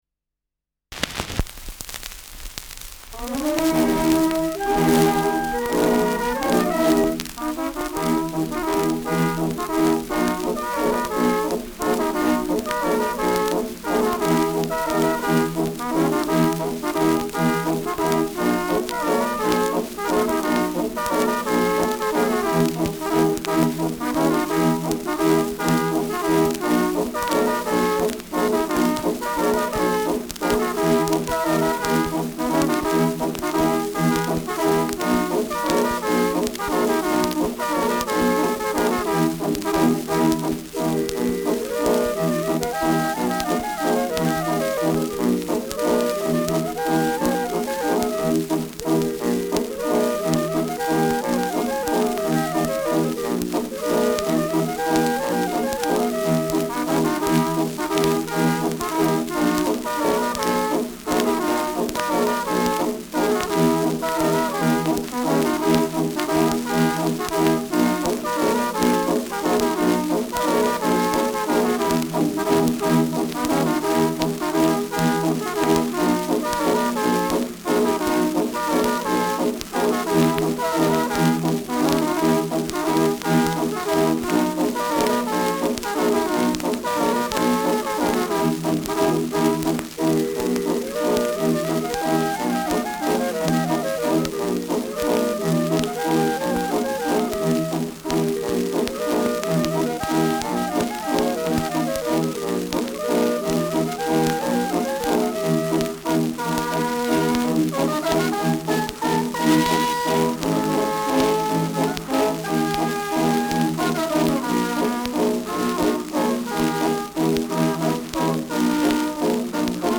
Schellackplatte
Stärkeres Grundrauschen : Gelegentlich leichtes bis starkes Knacken : Verzerrt an lauten Stellen
[München] (Aufnahmeort)